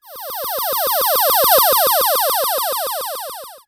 UFO07.wav